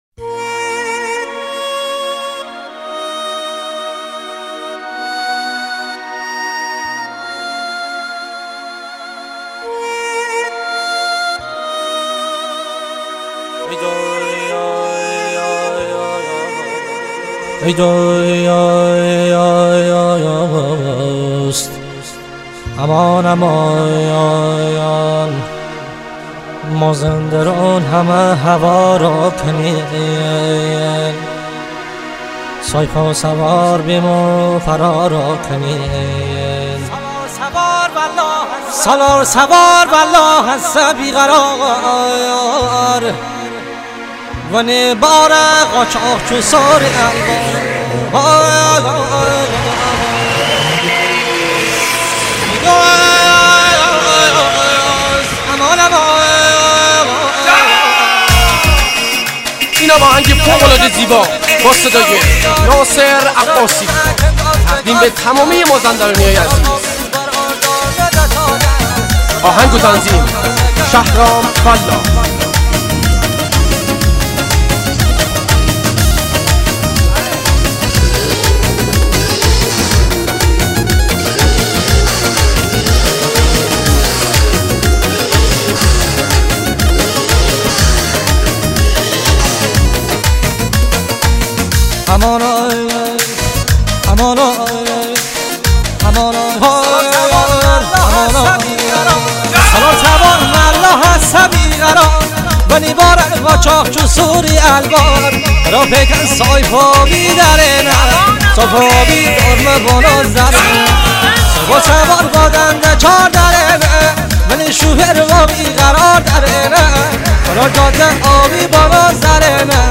آهنگ مازندرانی لاتی